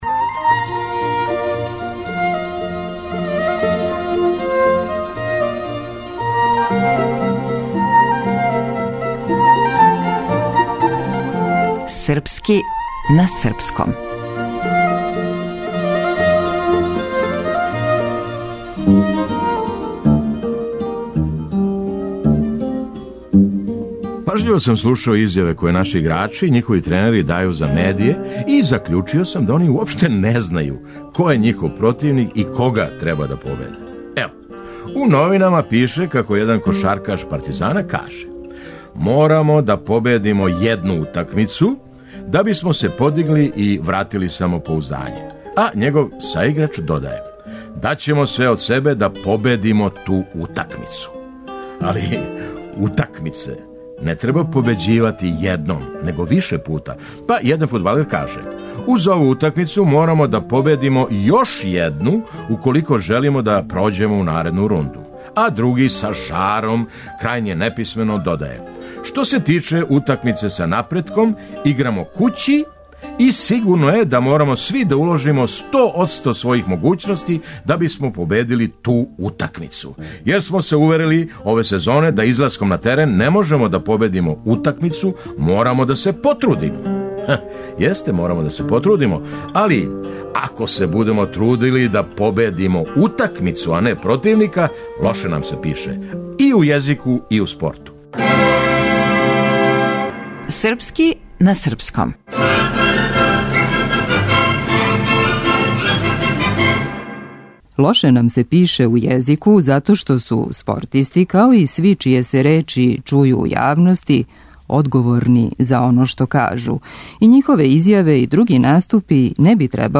Драмски уметник: Феђа Стојановић.